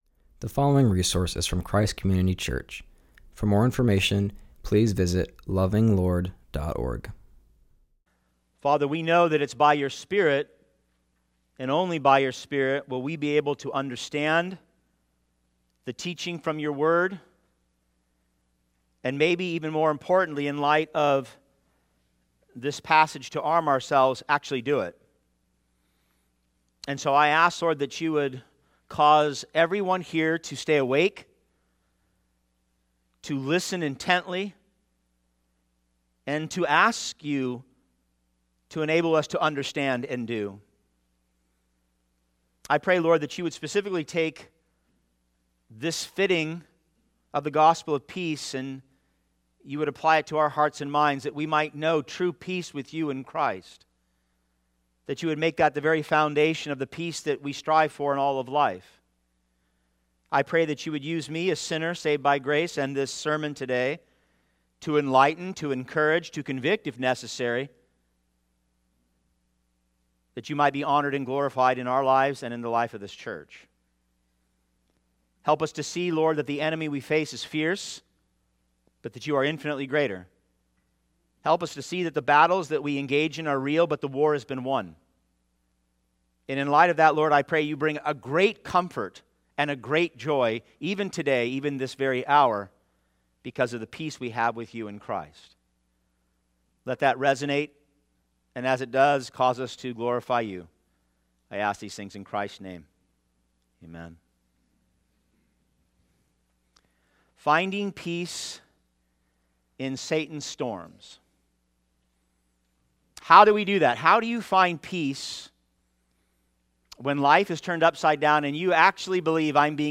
preaches from Ephesians 6:15.